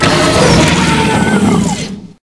CosmicRageSounds / ogg / general / combat / enemy / droid / bigatt2.ogg